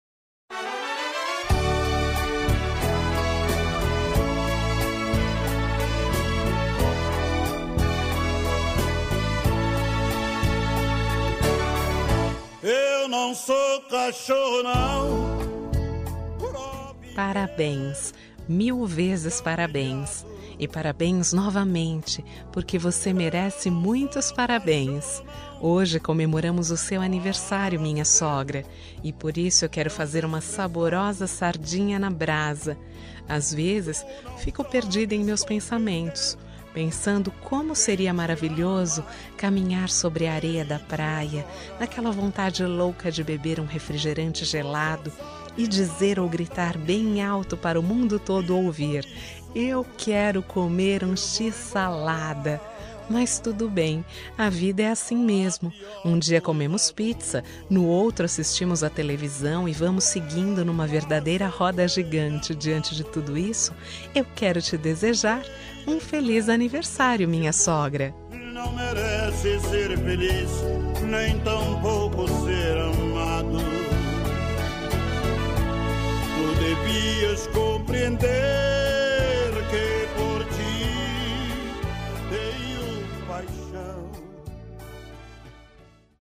Aniversário de Humor – Voz Feminina – Cód: 200116